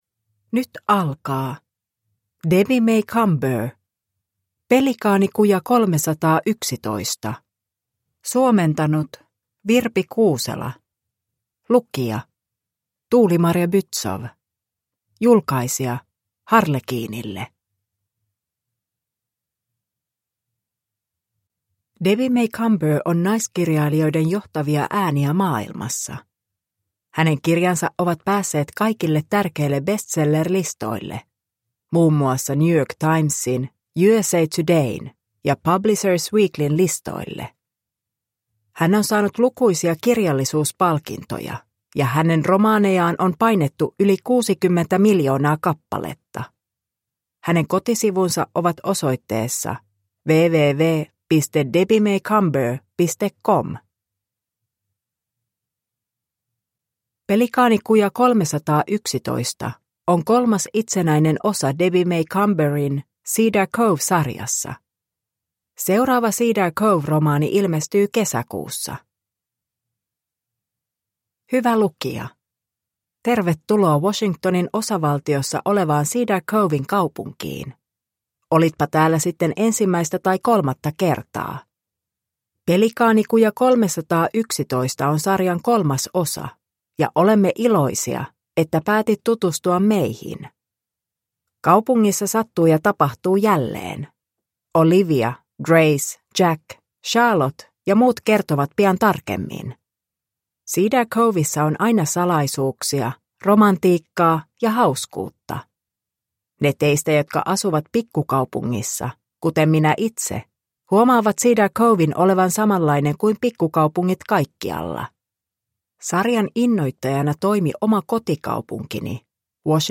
Pelikaanikuja 311 – Ljudbok – Laddas ner